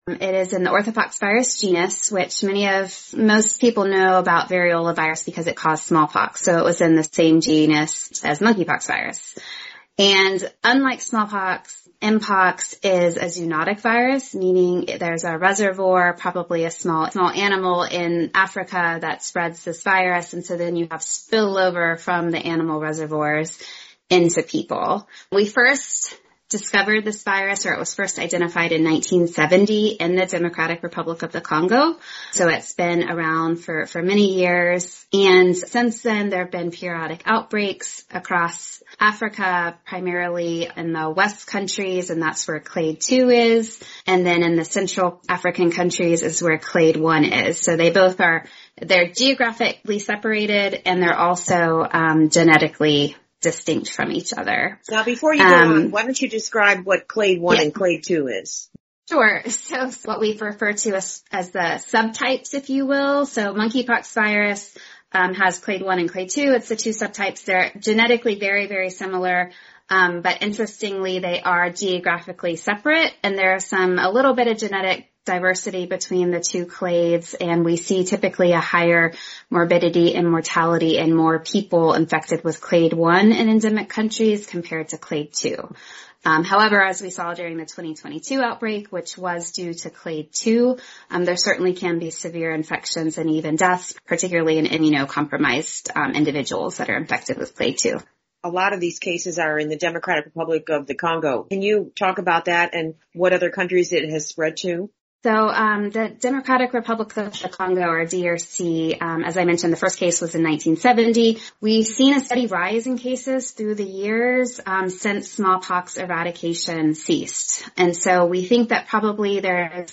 Africa News Tonight Clips